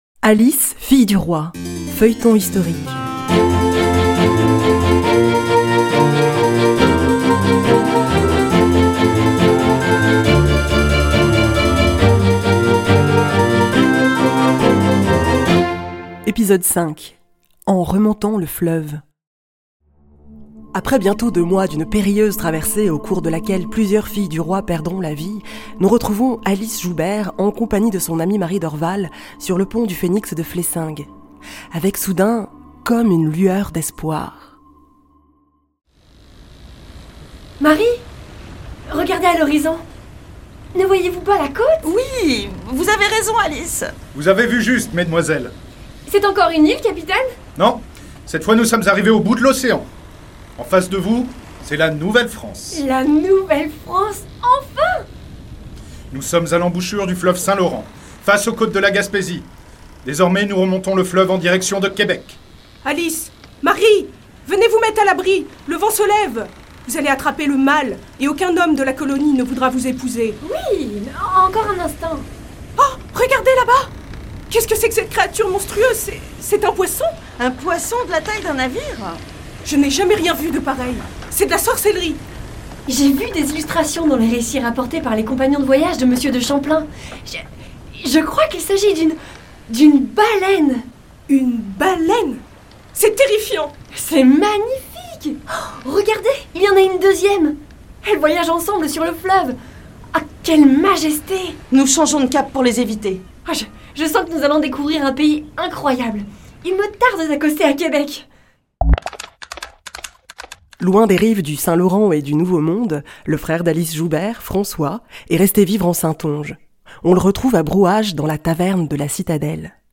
Avec, dans les rôles principaux :